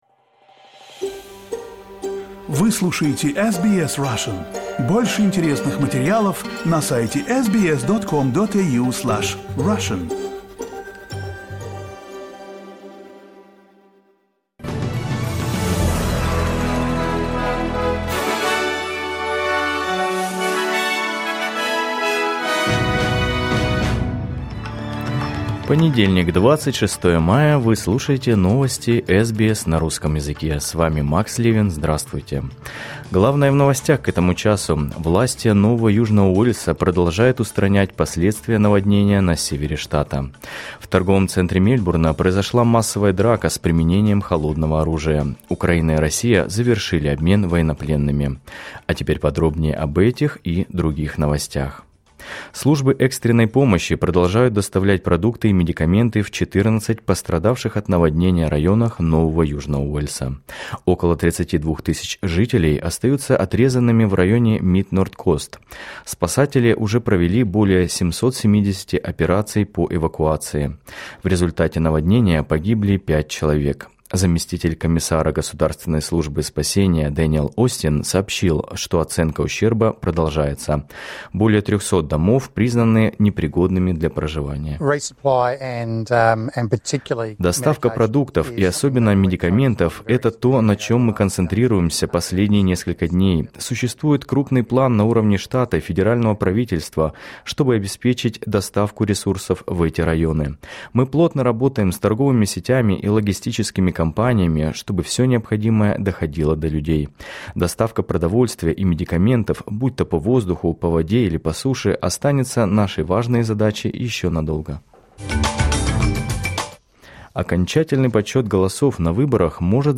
Новости SBS на русском языке — 26.05.2025